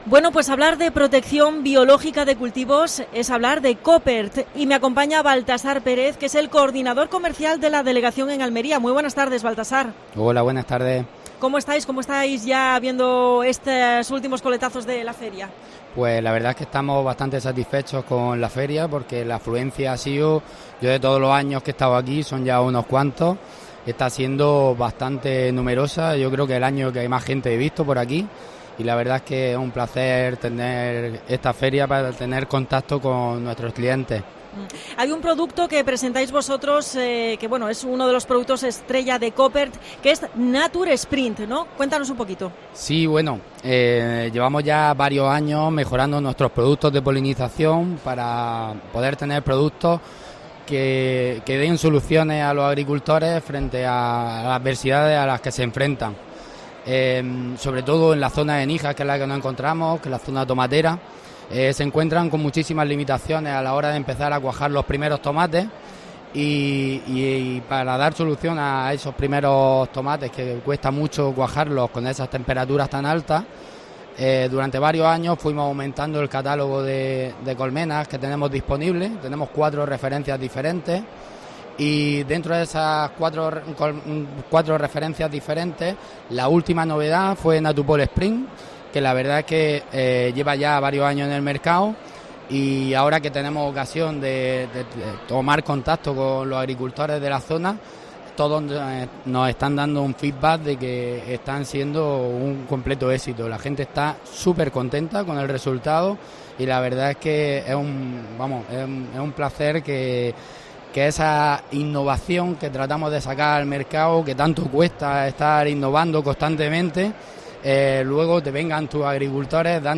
Especial COPE Almería desde ExpoLevante en Níjar.